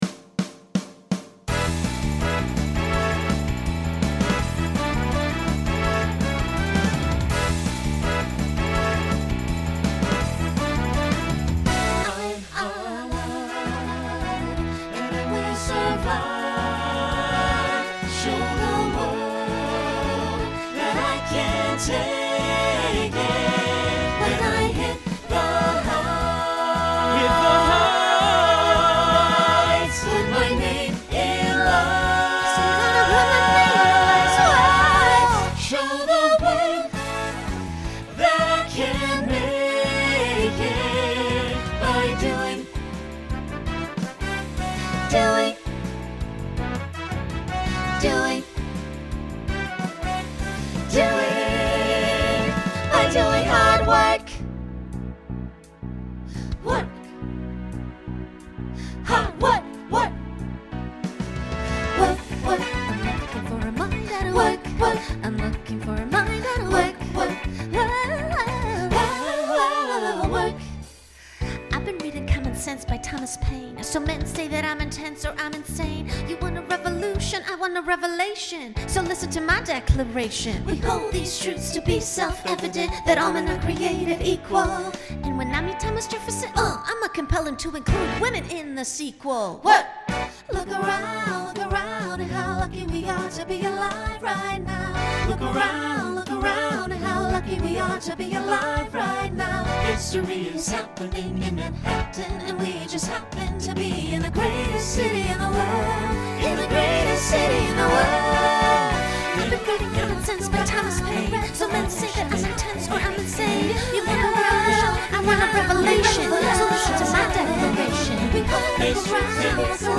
Genre Broadway/Film Instrumental combo
Story/Theme Voicing SATB